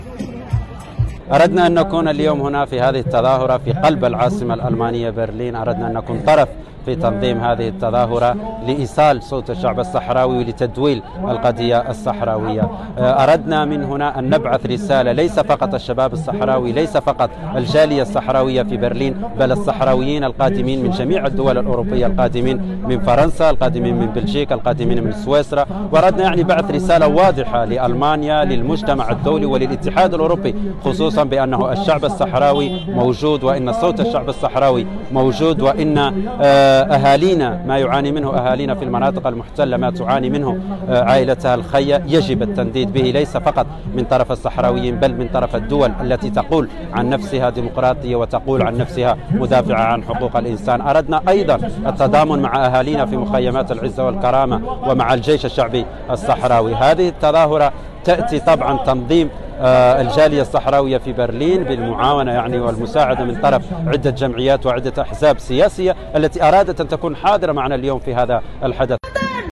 تظاهرة ببرلين للتنديد بذكرى الاجتياح المغربي للصحراء الغربية
ناشط وإعلامي صحراوي مشارك من إسبانيا